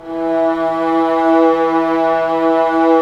Index of /90_sSampleCDs/Roland L-CD702/VOL-1/STR_Vlas Bow FX/STR_Vas Sordino